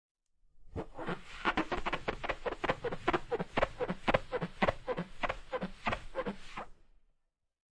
描述：抛光